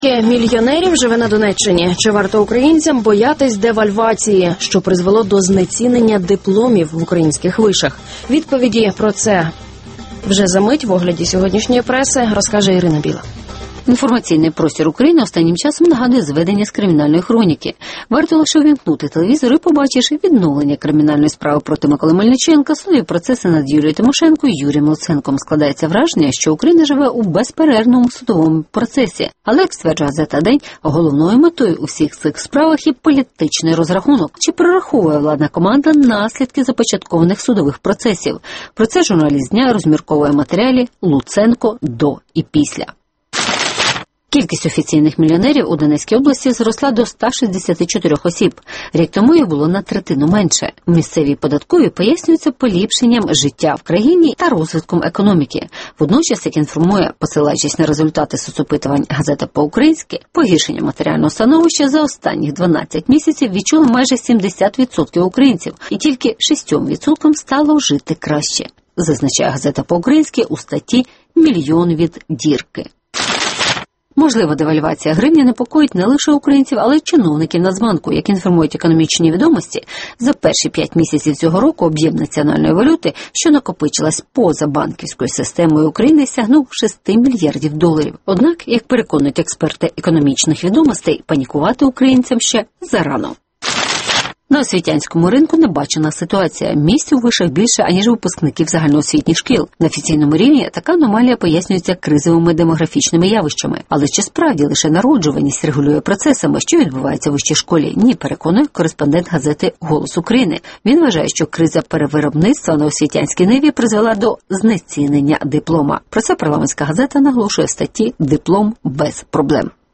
На Донеччині зростає кількість мільйонерів (огляд преси)